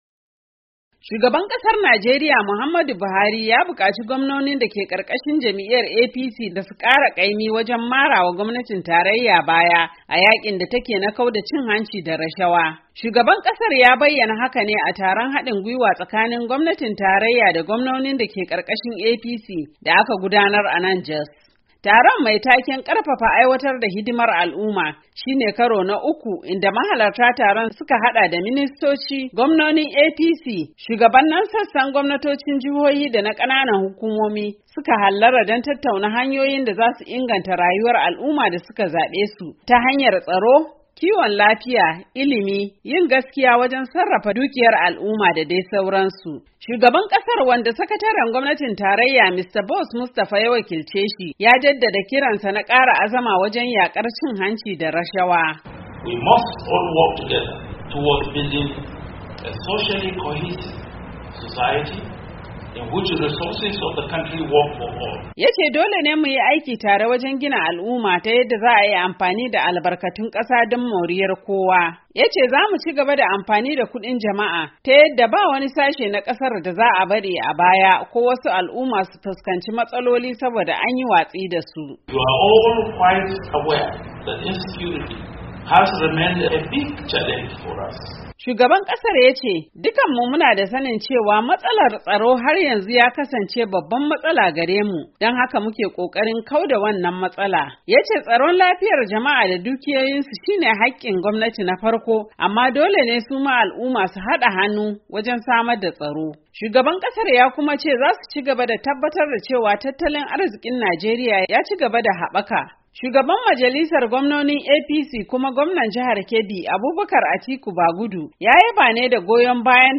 Shugaban kasar ya bayyana hakan ne yayin taron hadin gwiwa tsakanin gwanmonin da ke karkashin APC, da gwamnatin tarayya da aka gudanar a Jos, jihar Filato.